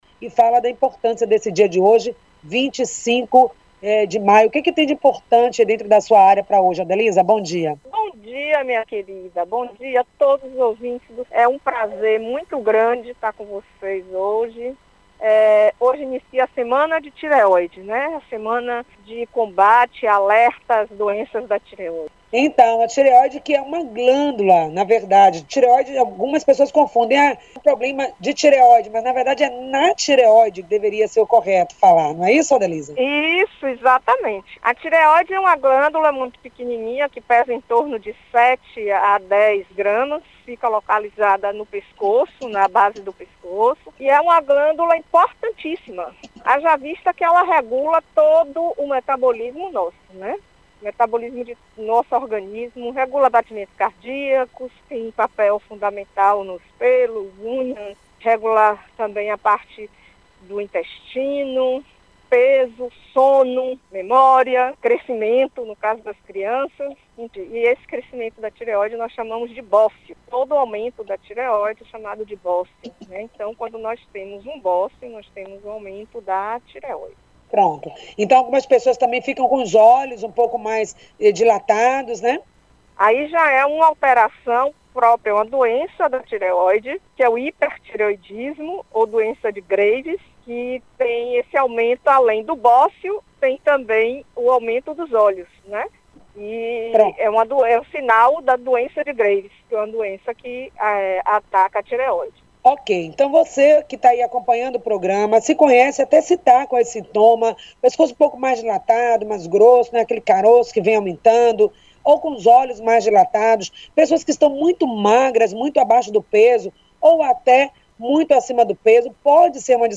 entrevistou a endocrinologista